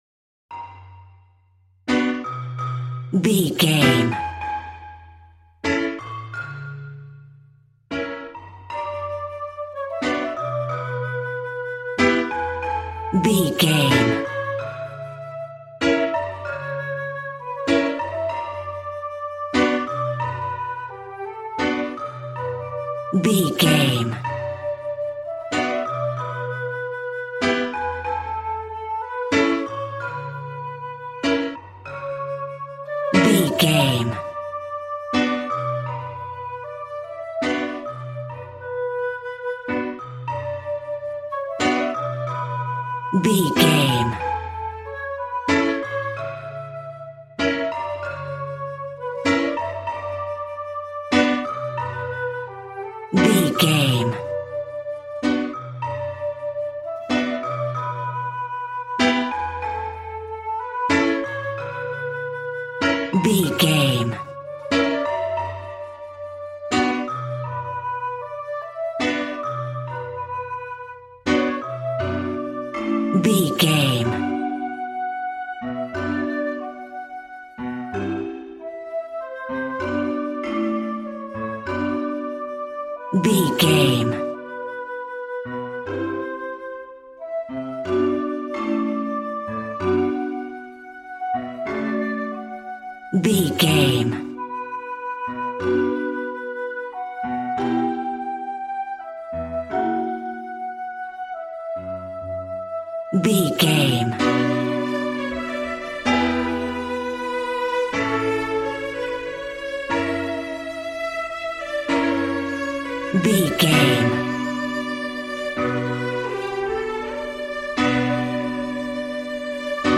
Aeolian/Minor
cheerful/happy
joyful
drums
acoustic guitar